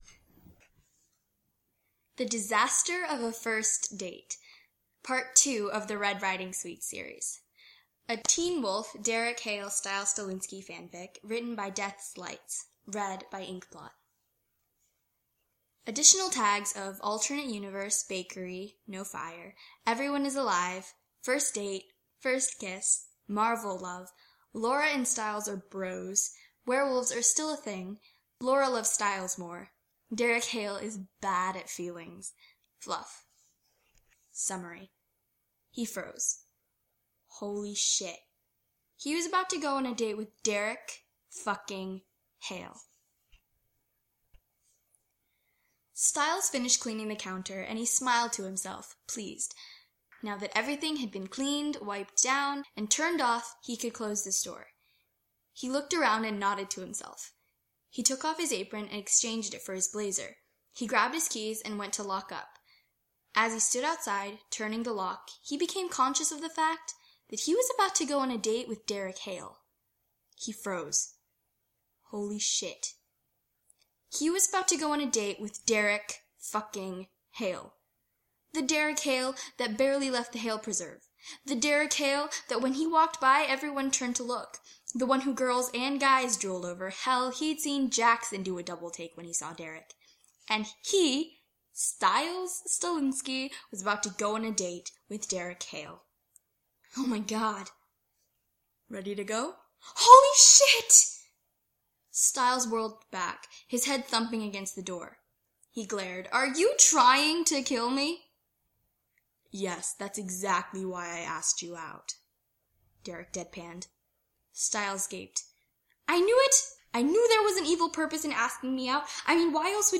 [podfic] The Disaster of a First Date by DeathsLights